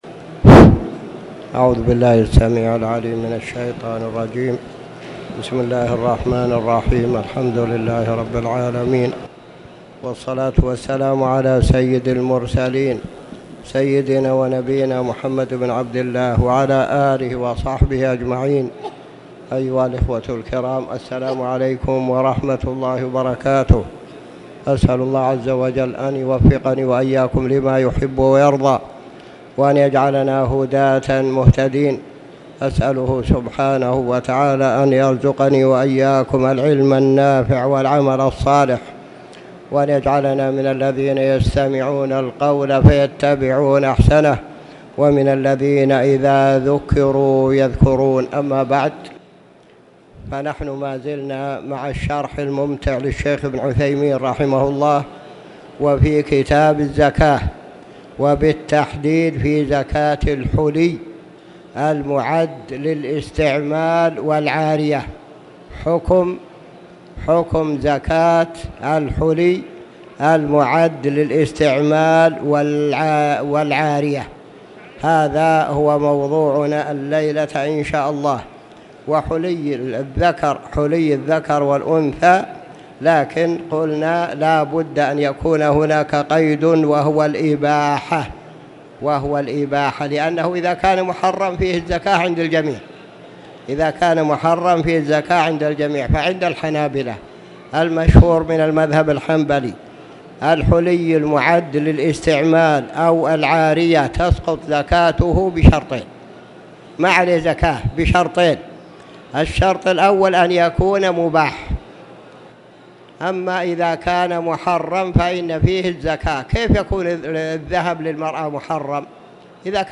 تاريخ النشر ٢٢ جمادى الآخرة ١٤٣٨ هـ المكان: المسجد الحرام الشيخ